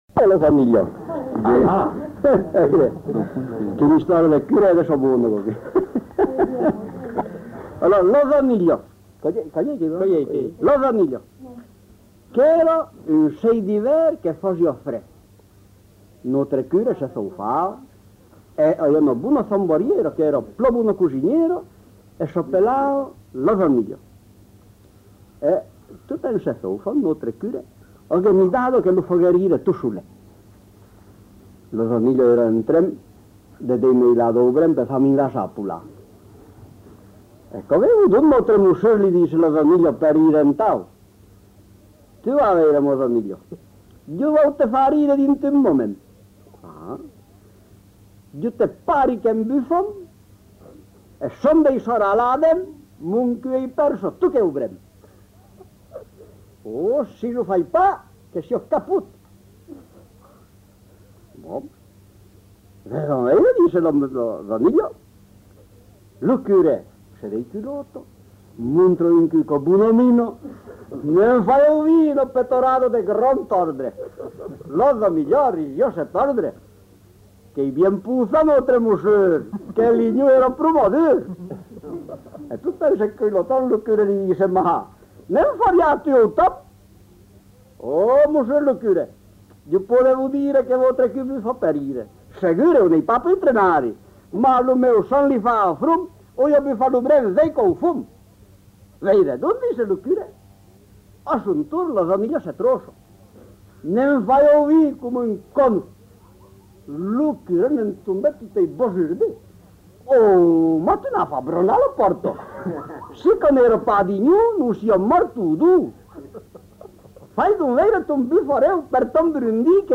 Aire culturelle : Périgord
Genre : conte-légende-récit
Effectif : 1
Type de voix : voix d'homme
Production du son : parlé